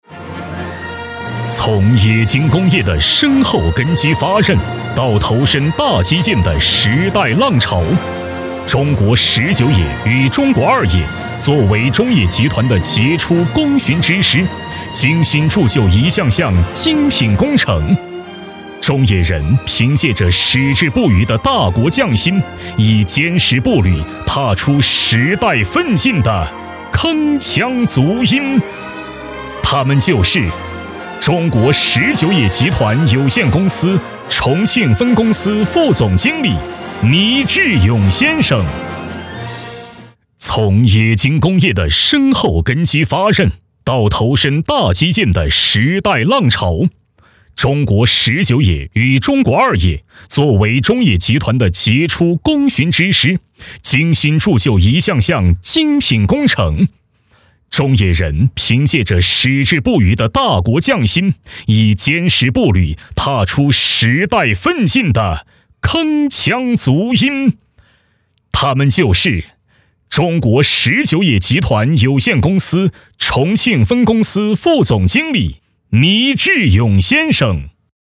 男国语318